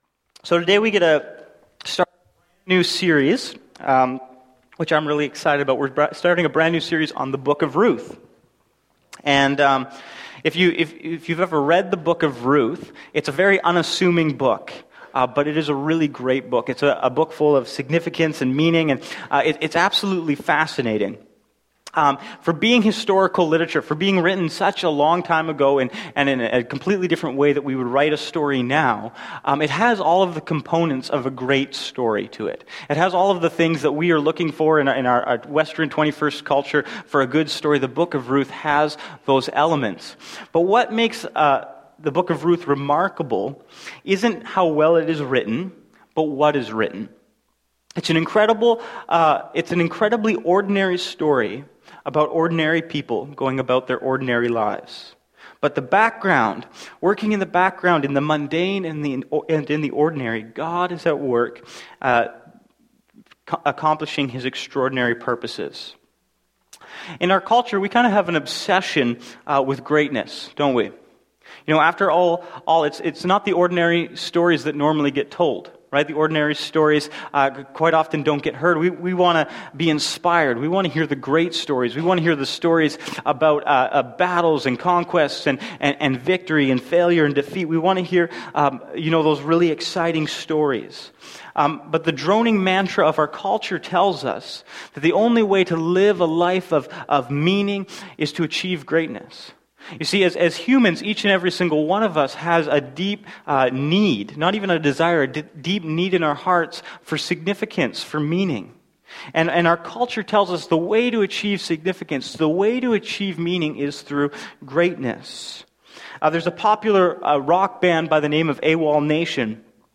Sermons | Bethel Church Ladysmith